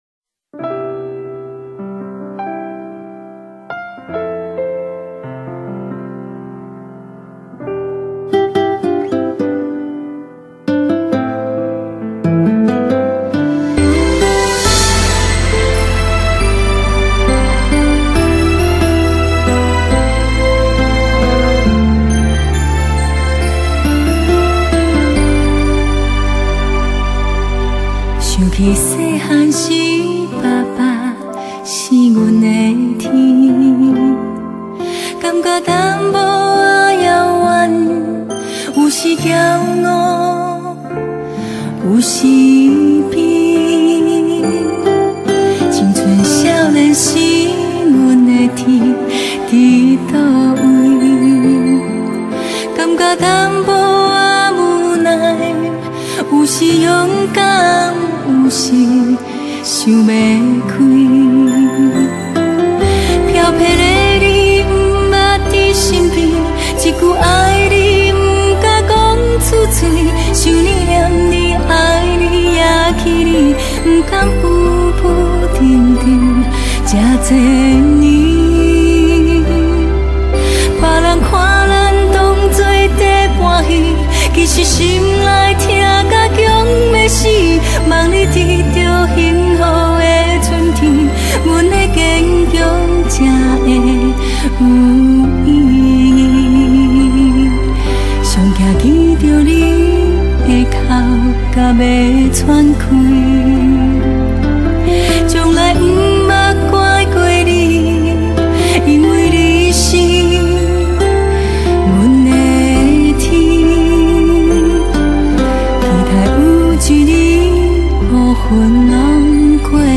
電音天后
再掀舞曲風潮  帶領大跳臺妹舞 嗶嗶嗶 嗶嗶…